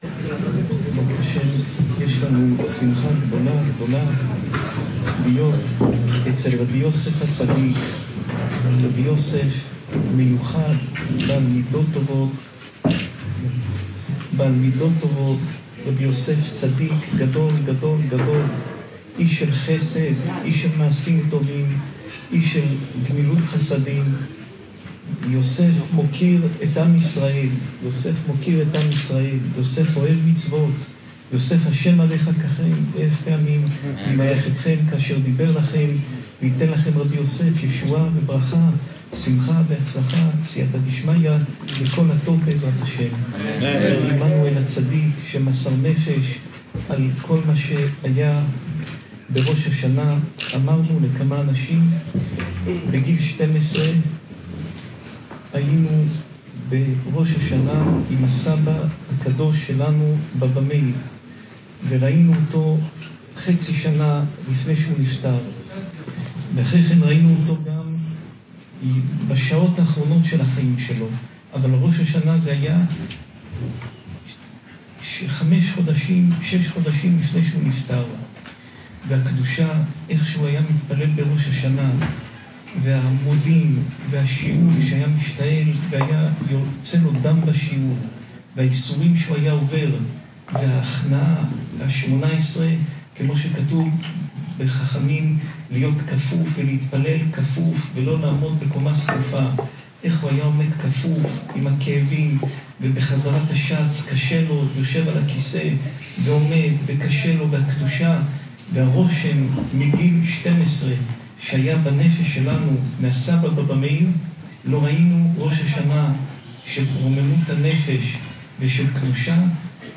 שעורי תורה